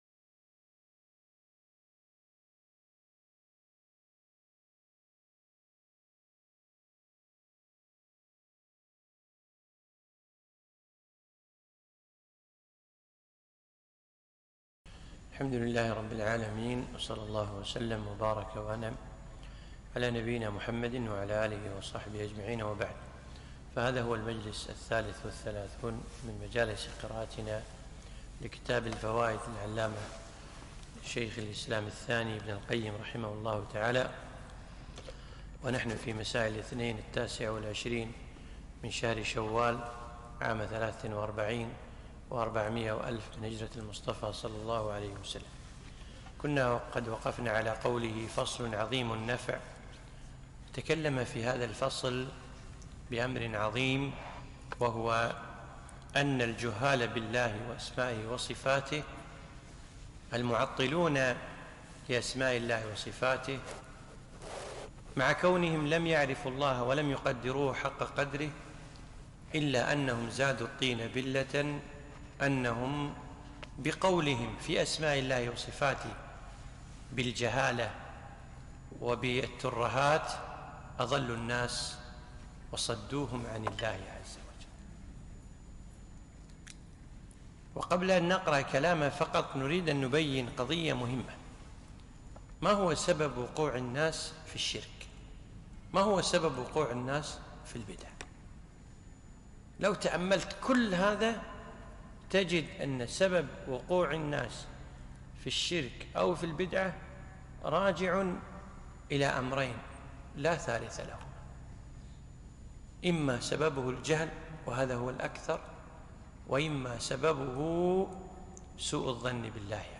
(٣٣) التعليق على كتاب الفوائد لابن القيم الجوزية رحمه الله - الدرس الثالث والثلاثون